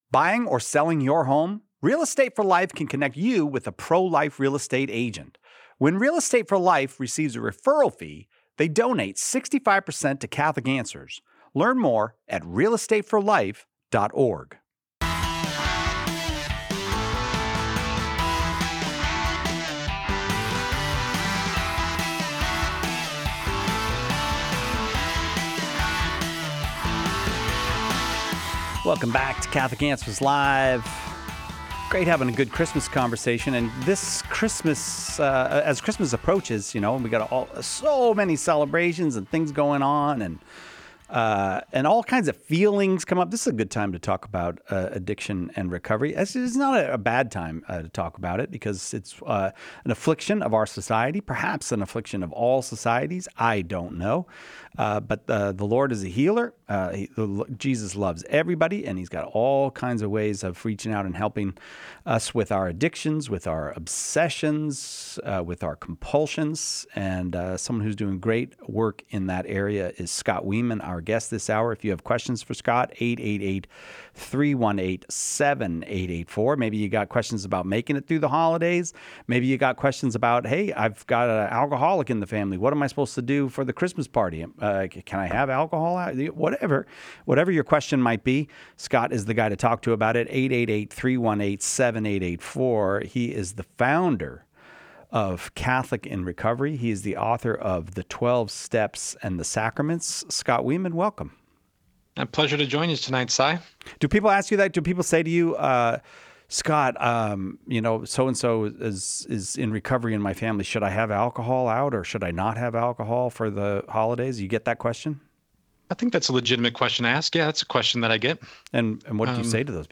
How Can I Return to The Church? Addiction and Recovery AMA